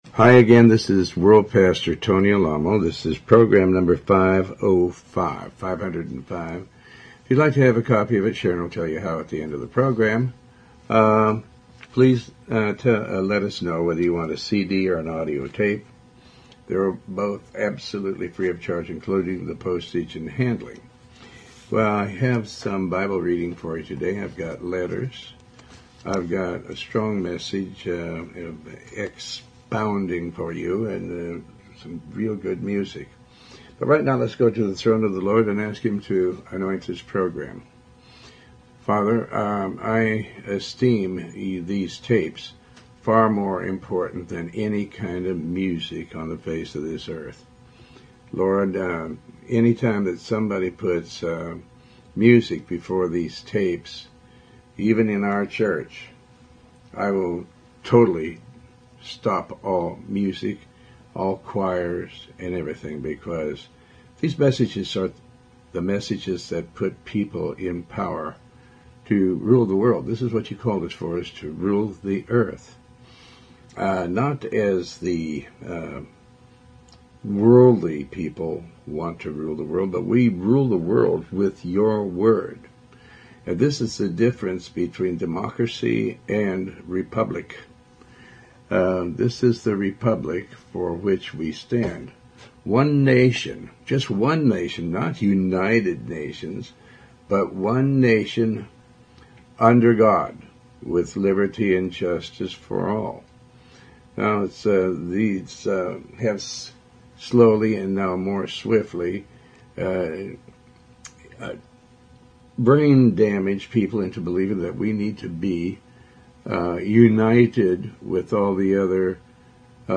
Talk Show Episode, Audio Podcast, Tony Alamo and Program 505 on , show guests , about Faith,pastor tony alamo,Tony Alamo Christian Ministries, categorized as Health & Lifestyle,History,Love & Relationships,Philosophy,Psychology,Christianity,Inspirational,Motivational,Society and Culture